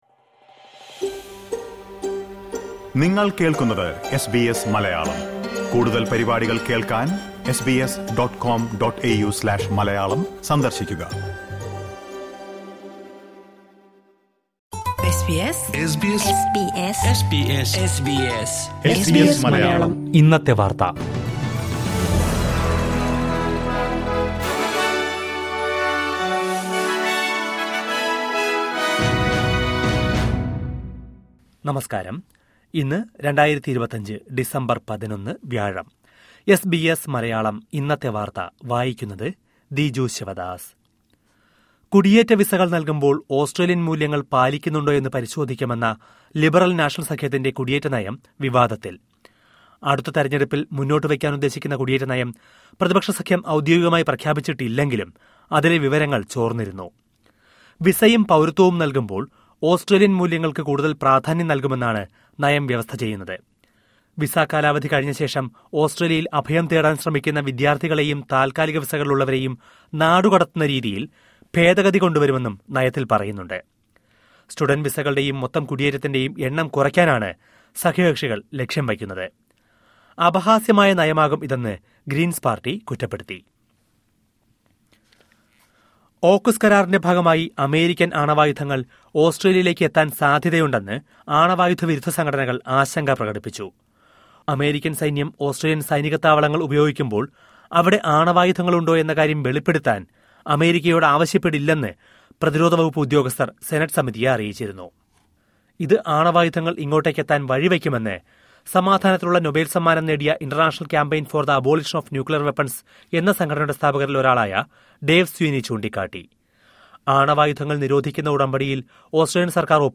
2025 ഡിസംബർ 11ലെ ഓസ്ട്രേലിയയിലെ ഏറ്റവും പ്രധാന വാർത്തകൾ കേൾക്കാം...